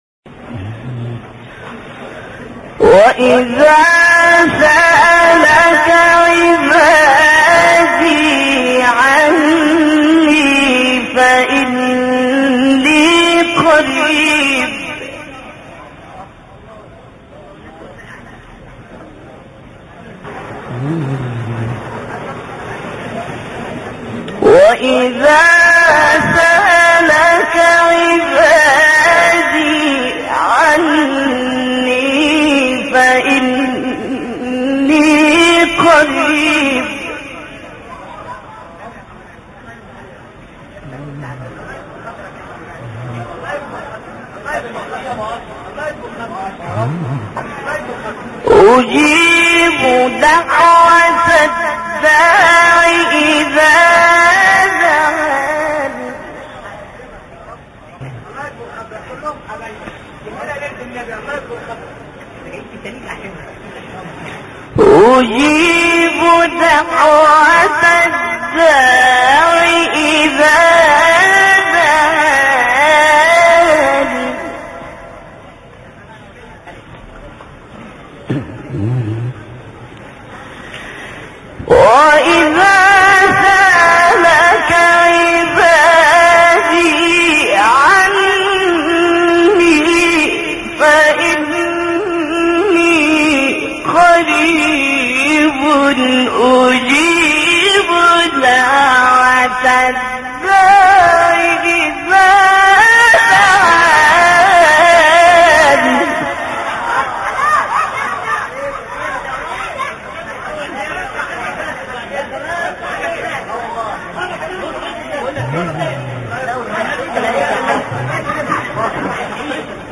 تلاوت زیبا و دلنشین استاد عبدالمنعم طوخی از سوره مبارکه بقره + صوت
کانون خبرنگاران نبأ: استاد عبدالمنعم طوخی یکی از قاریان و مداحان مصر است که به سبک استاد مصطفی اسماعیل تلاوت می‌کند؛ تلاوت ایشان در بین عموم مردم از محبوبیت قابل وصفی برخوردار است. بخش چندرسانه‌ای مقطع صوتی زیبا از سوره مبارکه بقره بازنشر می‌دهد.